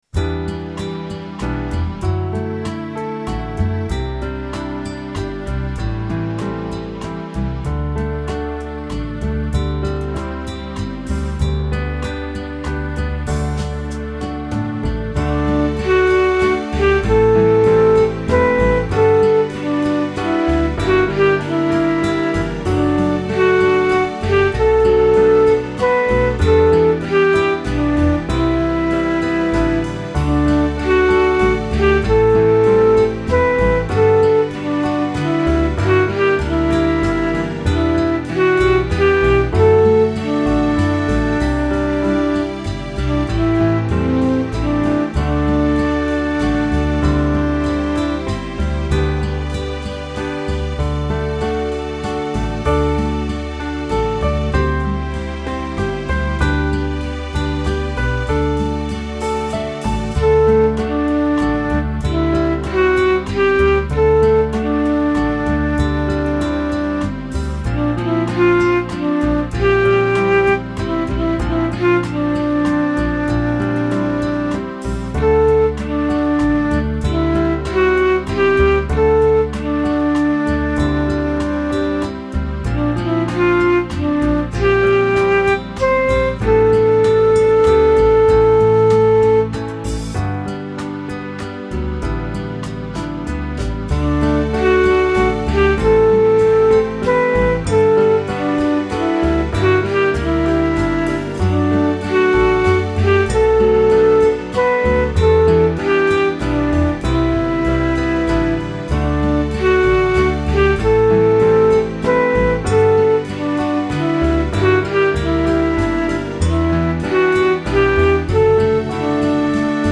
This is a Eucharist song that again has the cantor (or choir) – assembly conversation that we would usually ignore and just have the assembly sing the lot.
The fifth verse is really a bridge in a different key if you get to it.
This church has lovely accompaniment but does struggle a little.